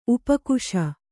♪ upa kuśa